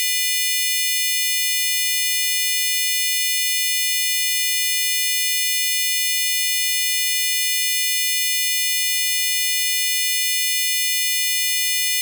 Liknande bilder som i första tråden men nu med en multiton bestående av allehanda sinusar från 2k till 20k med inharmoniska avstånd.
Bild 1, multiton.